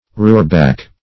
Roorback \Roor"back\, Roorbach \Roor"bach\, n.